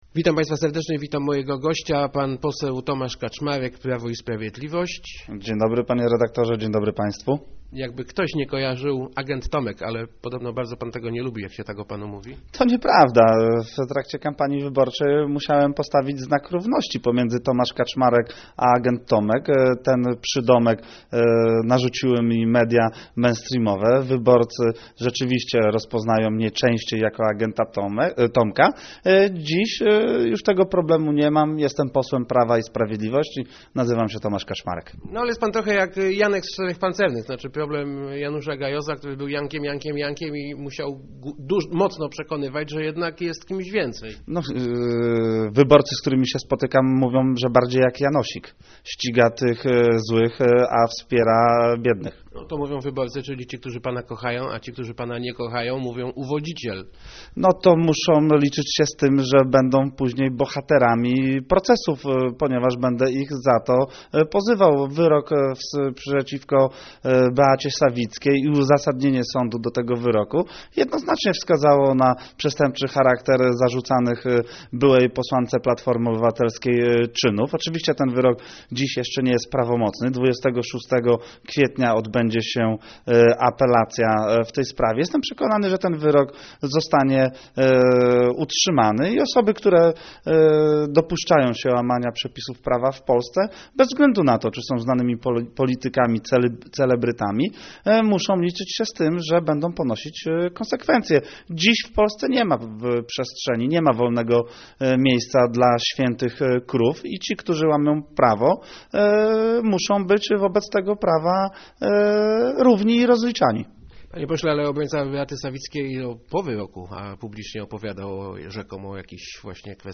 Mamy w Polsce bardzo dobrych i niezależnych prokuratorów - mówił w Rozmowach Elki poseł PiS Tomasz Kaczmarek. Jego zdaniem budzi to nadzieję, że korupcja w polityce, niezależnie od barw partyjnych, może być skutecznie zwalczana.